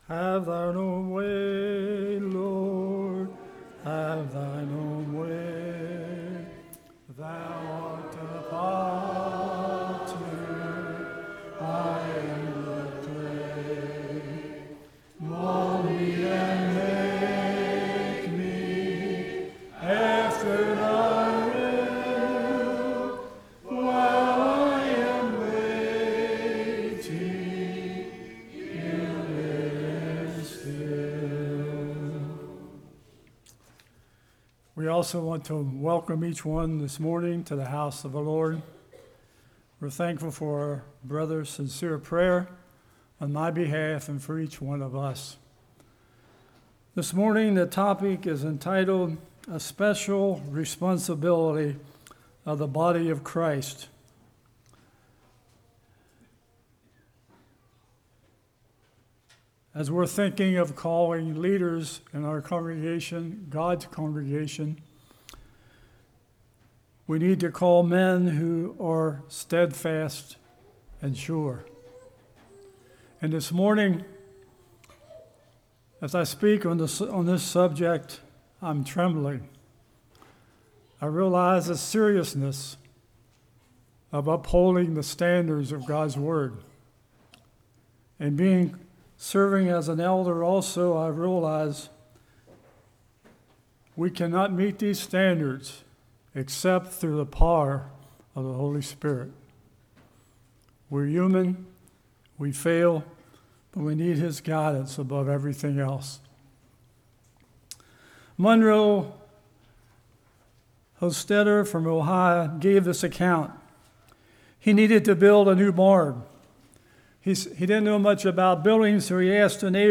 Mark 13:33-37 Service Type: Morning Spiritual Maturity Moral character Public reputation « Elders & Deacons Role of Life